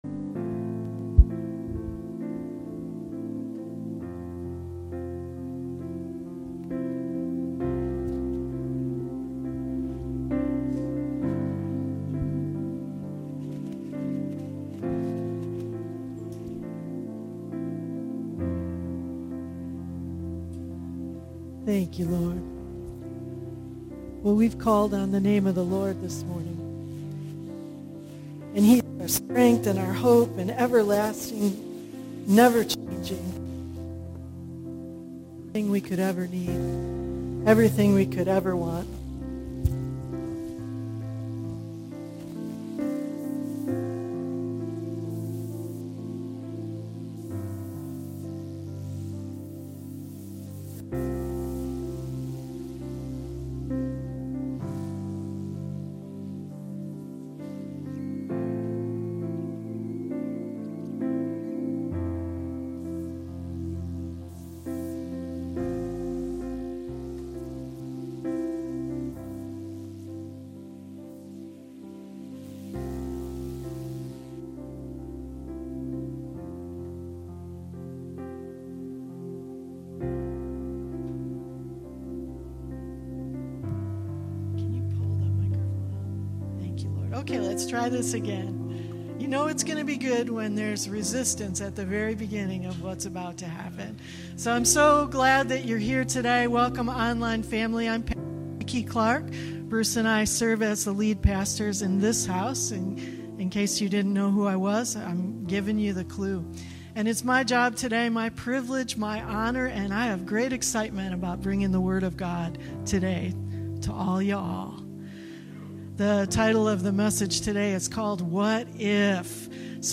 Messages